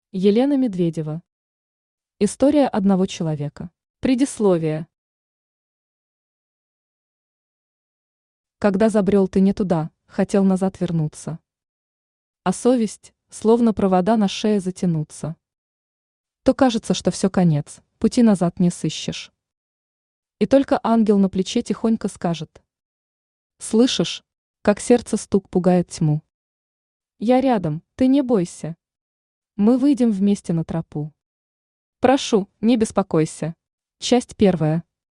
Аудиокнига История одного человека | Библиотека аудиокниг
Aудиокнига История одного человека Автор Елена Ивановна Медведева Читает аудиокнигу Авточтец ЛитРес.